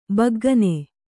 ♪ baggane